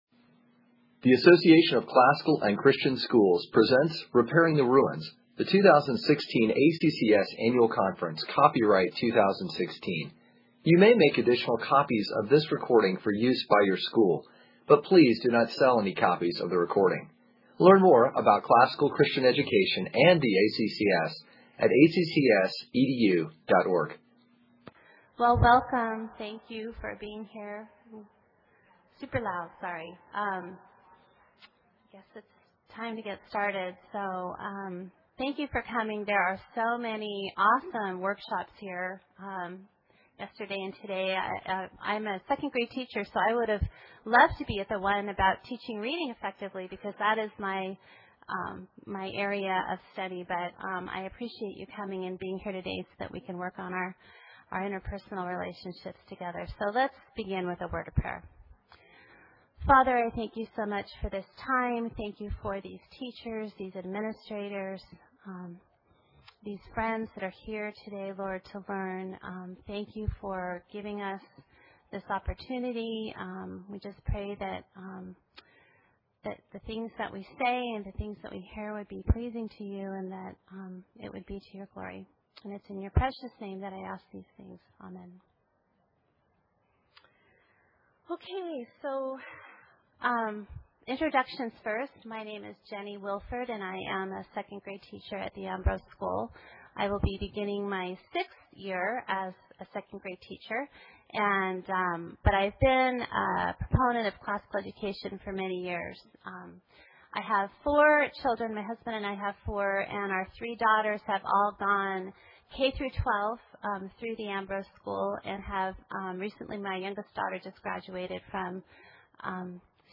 2016 Workshop Talk | 0:58:14 | All Grade Levels, General Classroom
Additional Materials The Association of Classical & Christian Schools presents Repairing the Ruins, the ACCS annual conference, copyright ACCS.